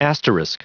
Prononciation du mot asterisk en anglais (fichier audio)
Prononciation du mot : asterisk